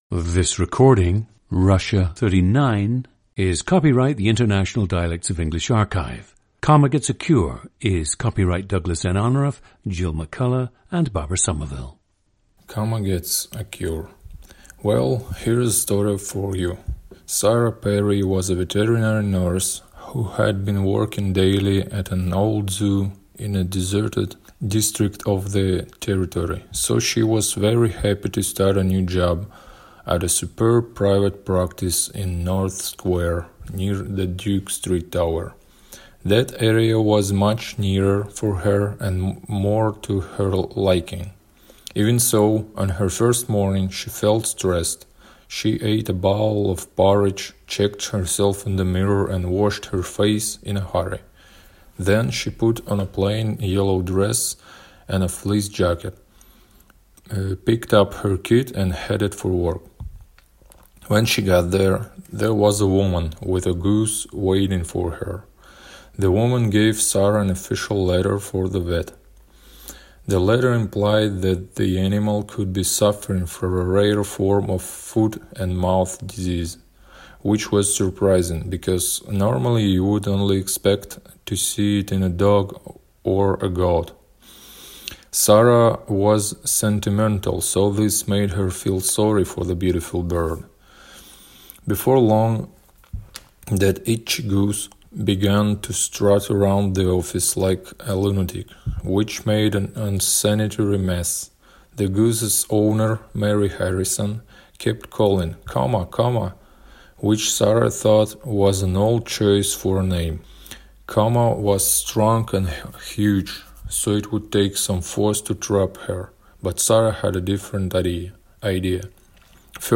PLACE OF BIRTH: Kineshma, Ivanovo, Russia
GENDER: male
OTHER INFLUENCES ON SPEECH: none
The recordings average four minutes in length and feature both the reading of one of two standard passages, and some unscripted speech.